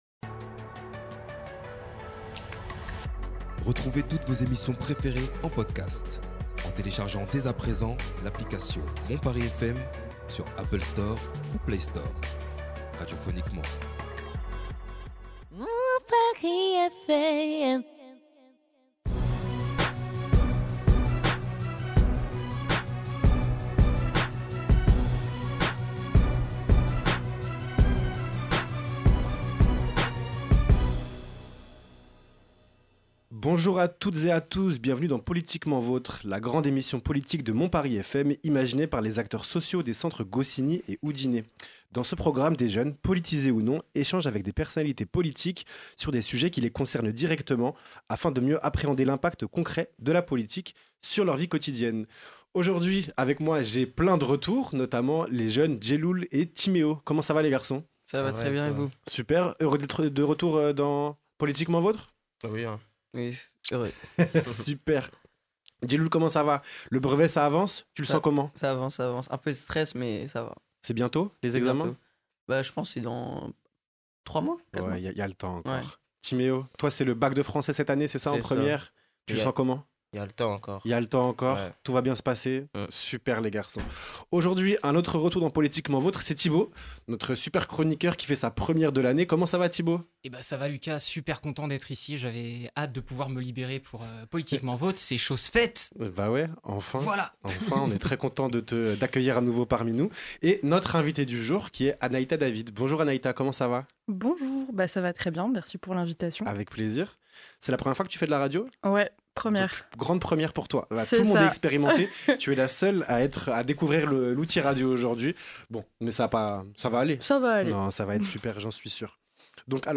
des jeunes, poltisés ou non, ainsi que nos chroniqueurs font face à une personnalité politique.